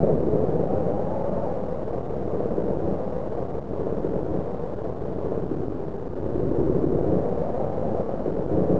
warwind.wav